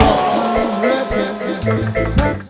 DUBLOOP 03-L.wav